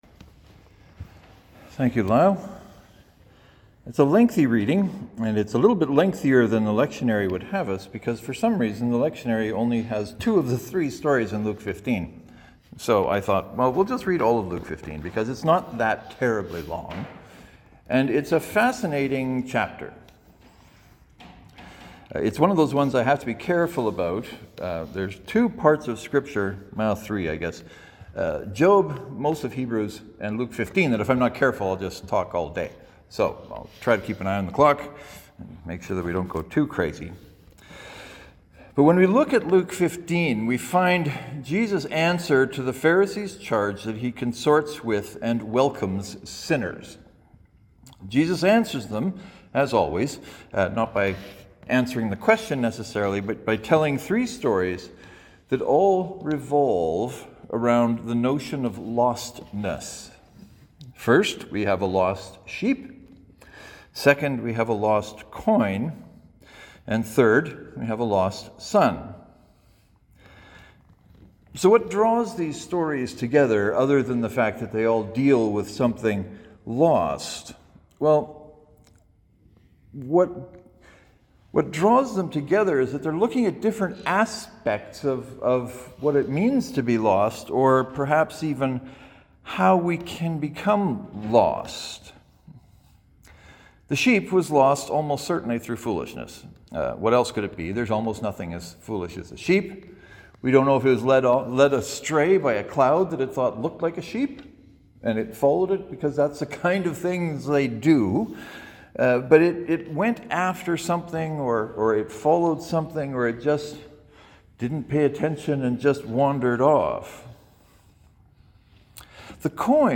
As you’ll hear in the sermon, I divert (sort of) from the Lectionary this week in that the Gospel lesson is from Luke 15 but leaves out one of the stories. So rather than talk about a lost sheep and a lost son, I decided to talk about all three losses by having us read the whole of chapter 15 of Luke.